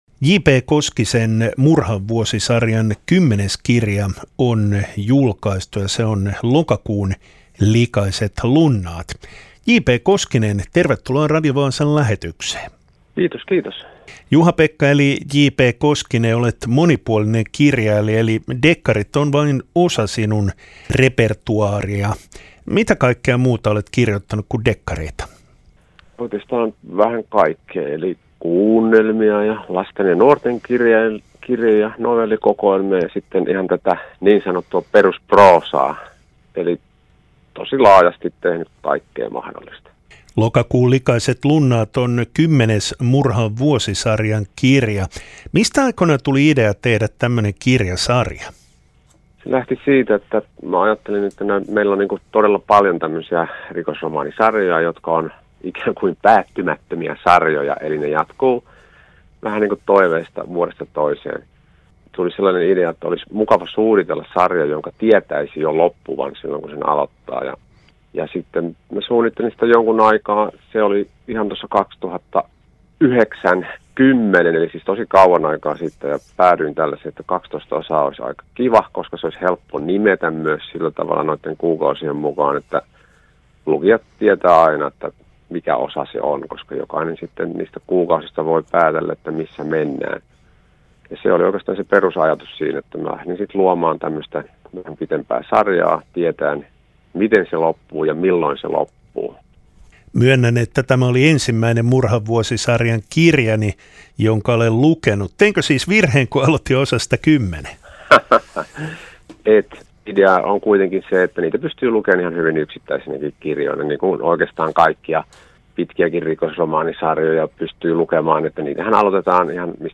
haastattelussa.